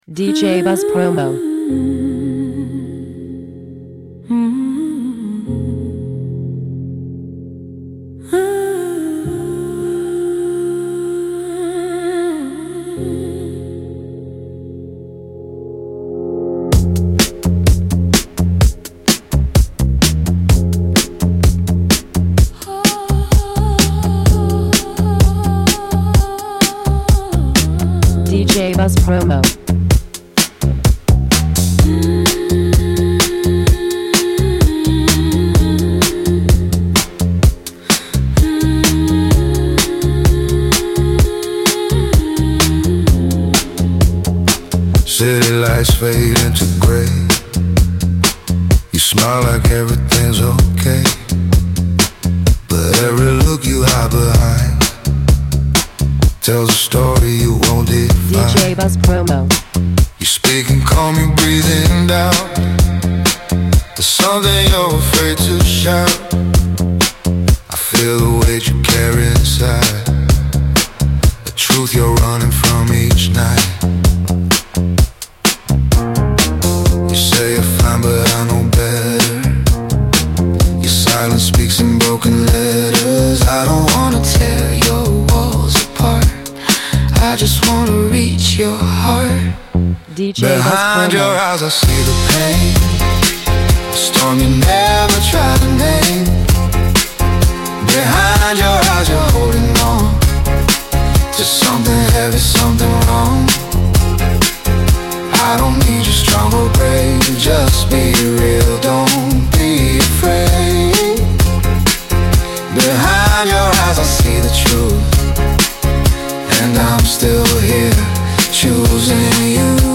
Driven by atmospheric production and intimate lyrics
Original Mix